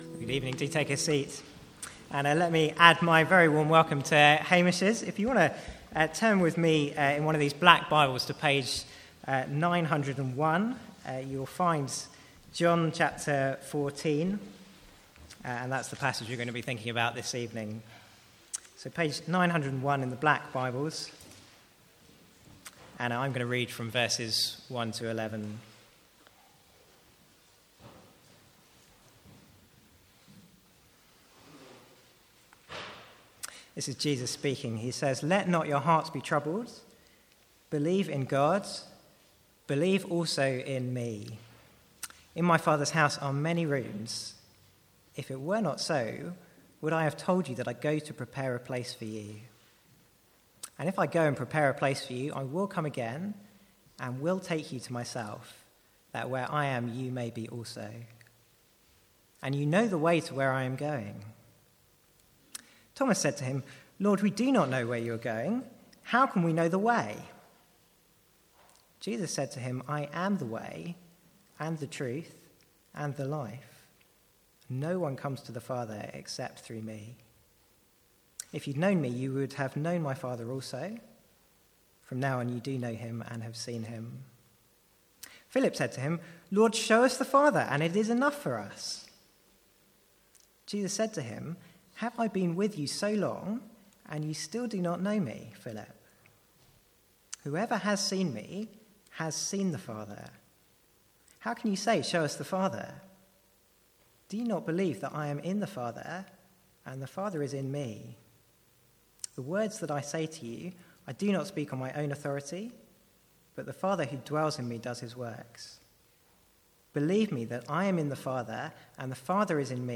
From the Sunday evening 'Tough Questions' guest series (with Q&A).